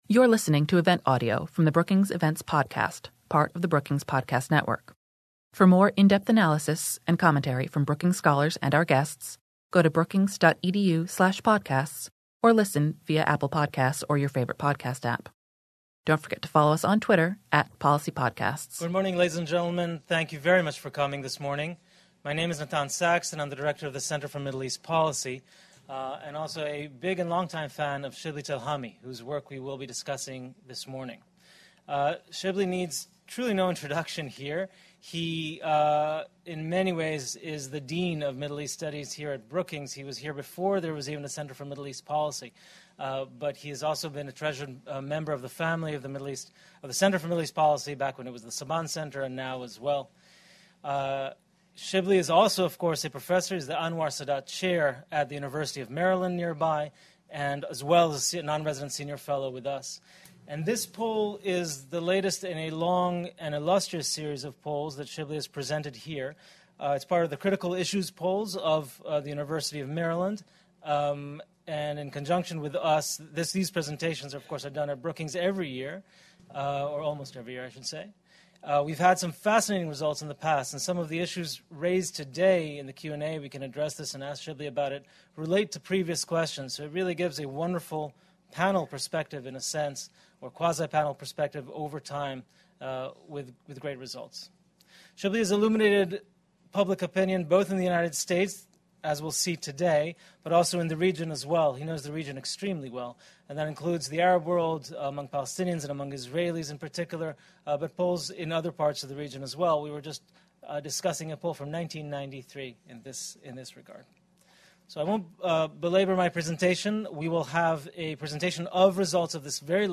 Topics covered included the U.S. withdrawal from Syria, the U.S.-Taliban peace process, how the recent attacks on Saudi oil facilities impacted views on Iran policy, and the Boycott, Divest, and Sanction movement aimed at Israel. A discussion with panelists and audience questions followed the presentation.